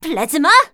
assassin_w_voc_plasmabuster01.ogg